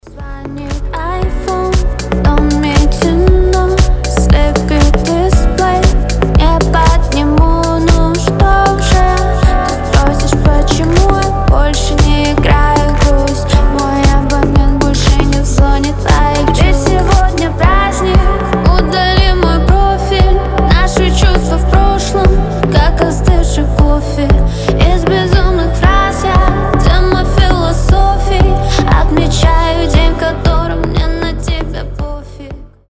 • Качество: 320, Stereo
грустные
мелодичные
красивый женский голос